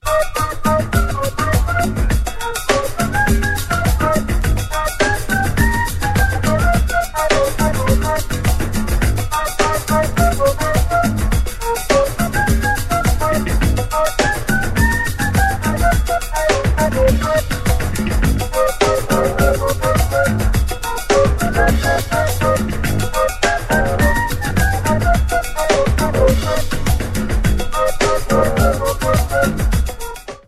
Supporto:   01 UNMIXED
Genere:   Afro | Funky